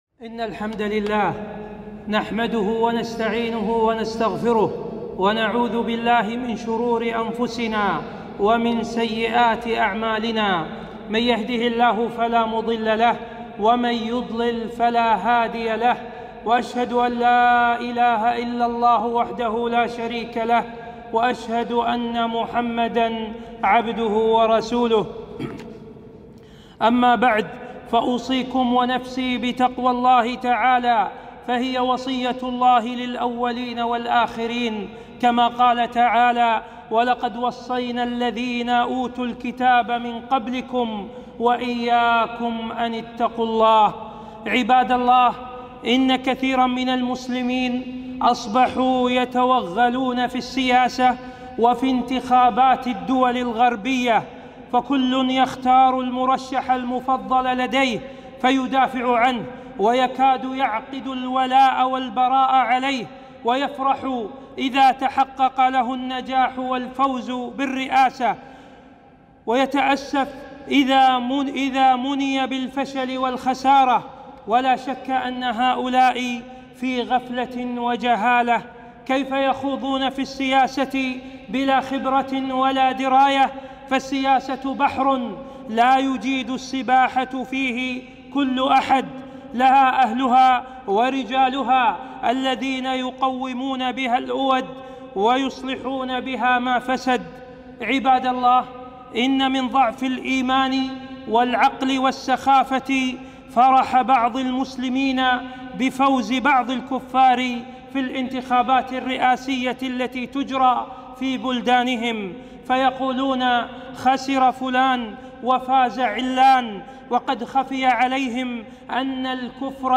خطبة - خطورة التوغل في السياسة